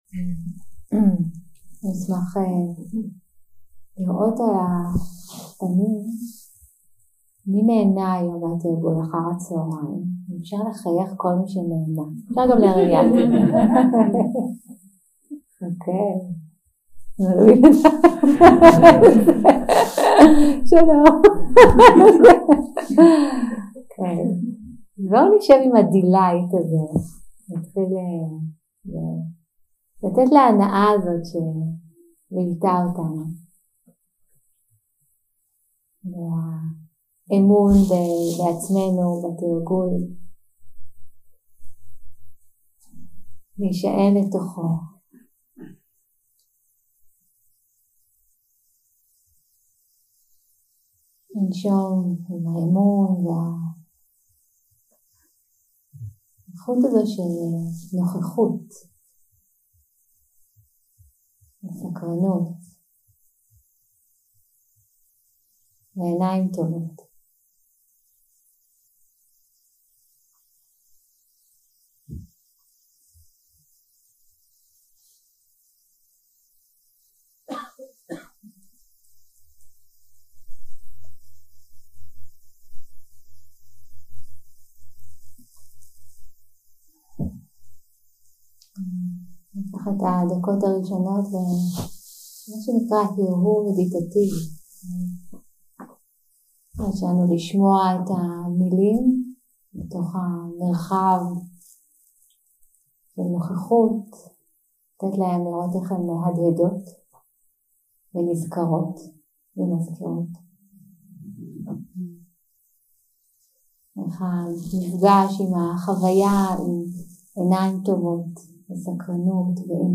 יום 6 – הקלטה 14 – ערב – שיחת דהארמה
Dharma type: Dharma Talks